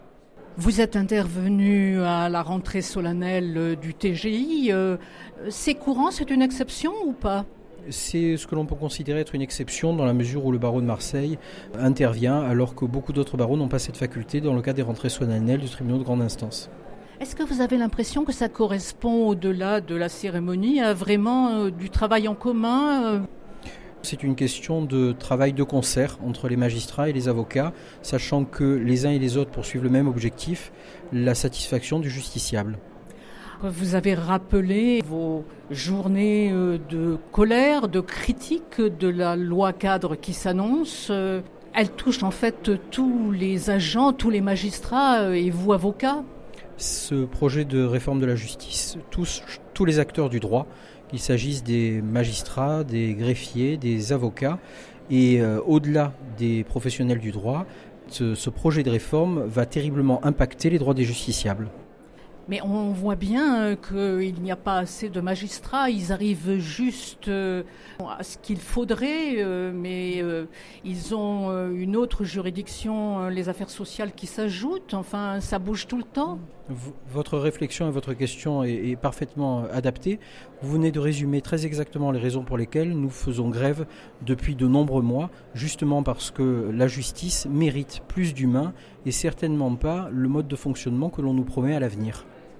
Magistrats, représentants de l’État, autorités civiles et militaires, acteurs du monde judiciaire, se sont réunis au sein Tribunal de grande instance de Marseille pour l’audience solennelle de rentrée 2019.